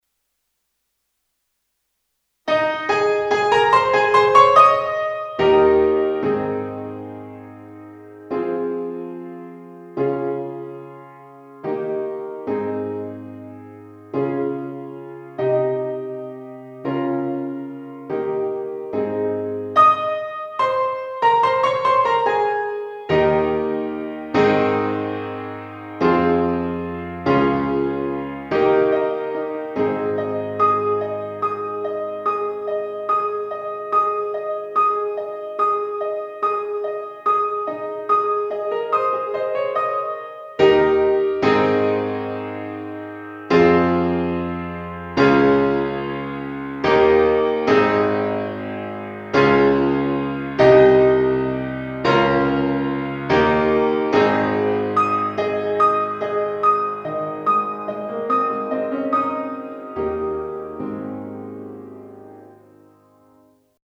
Simple-Gifts-Backing.mp3